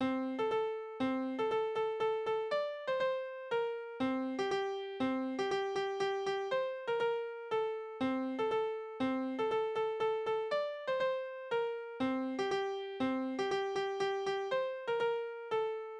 Tonart: F-Dur
Taktart: 2/4
Tonumfang: große None
Besetzung: vokal